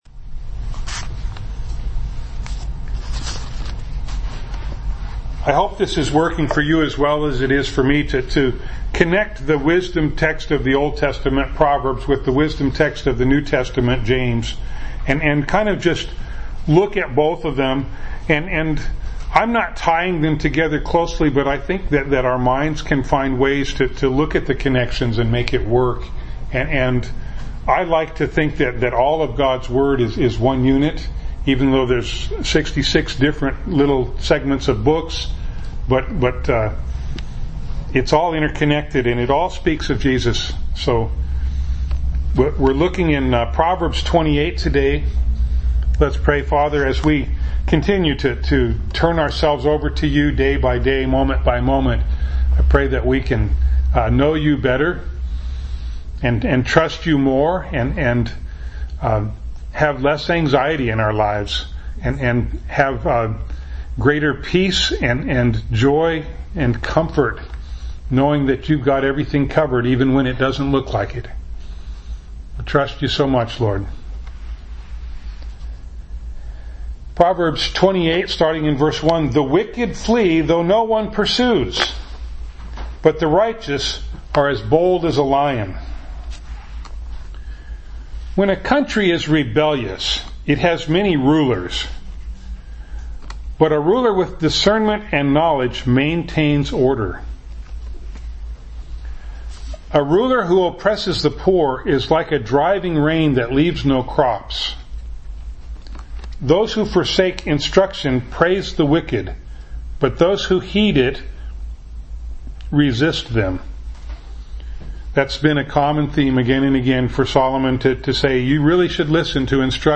James 2:20-26 Service Type: Sunday Morning Bible Text